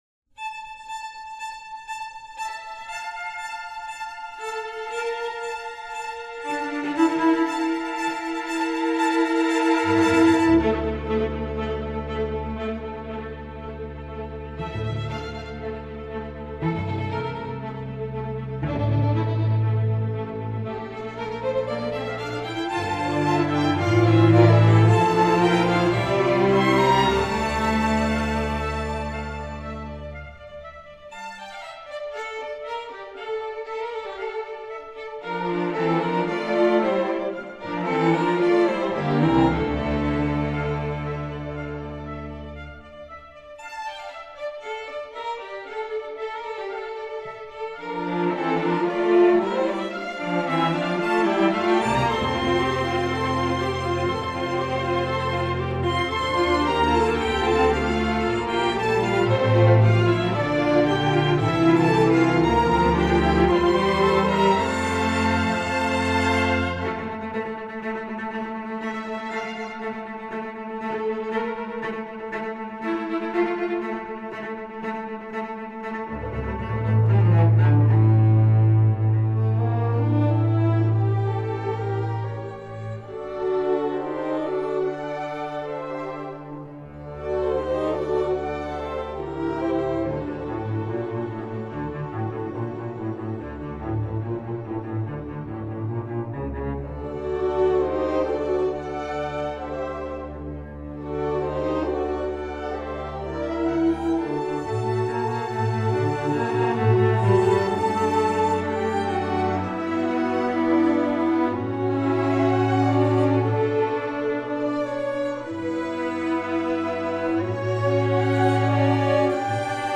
Instrumentation: string orchestra
instructional